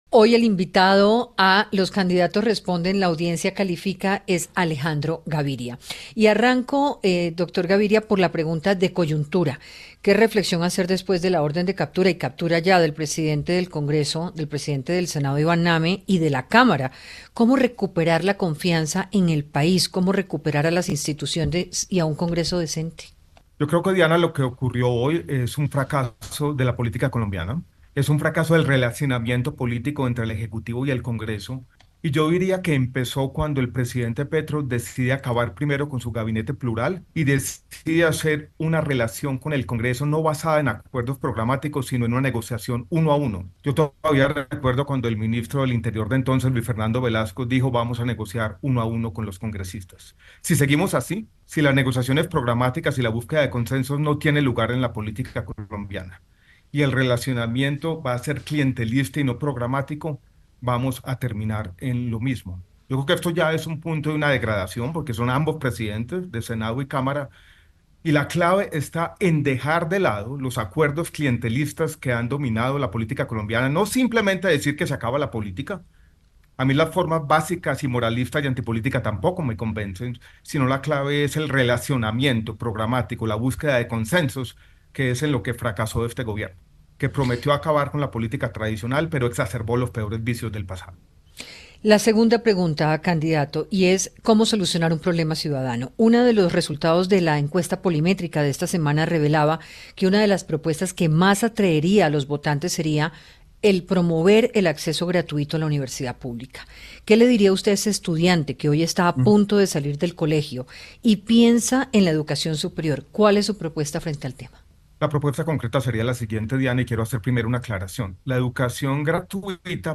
En diálogo con Hora20 de Caracol Radio en “Los candidatos responden, la audiencia califica” el precandidato Alejandro Gaviria, dijo que lo ocurrido con Name y Calle es la demostración de un fracaso de la política colombiana y del relacionamiento entre el Congreso y el Ejecutivo: